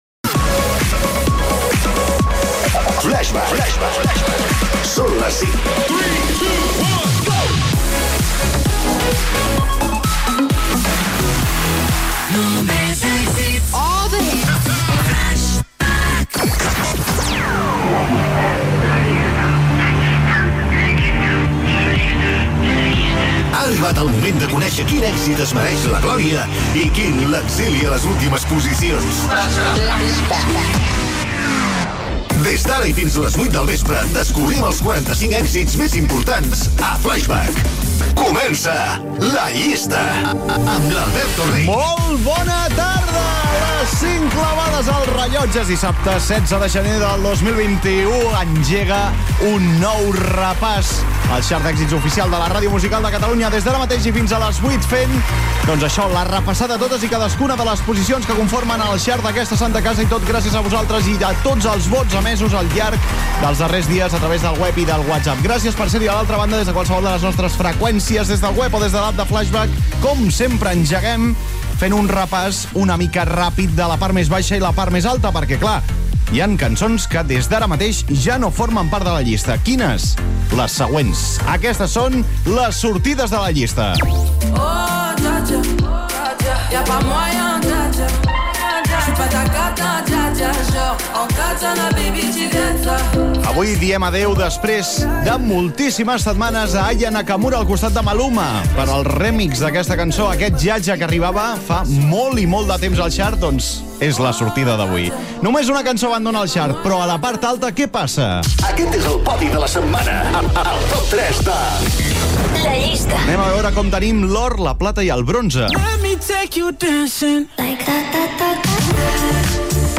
Hora, indicatiu, careta del programa, hora, data, presentació inicial, cançons que surten de la llista i cançons que estan a la part alta.
Musical